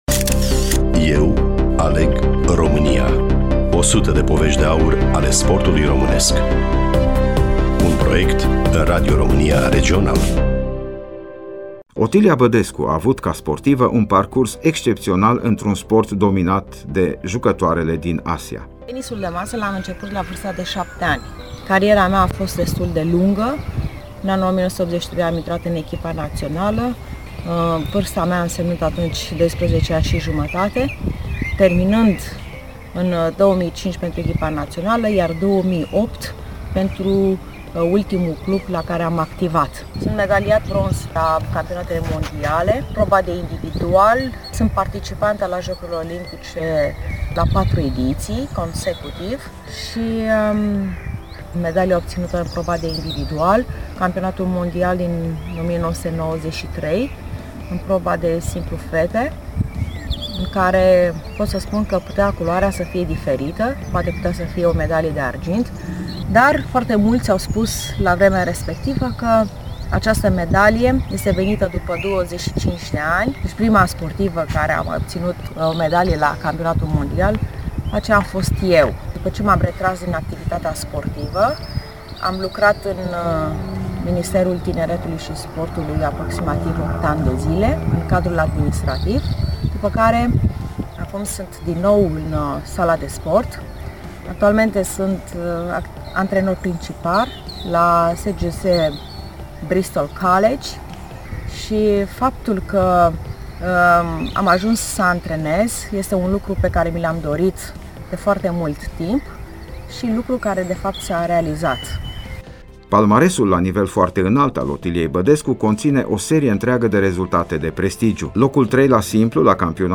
Studioul Regional Radio România Cluj